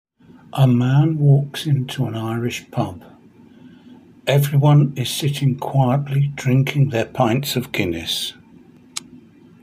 Each line is written in English and then in Spanish and has a recording of me reading it.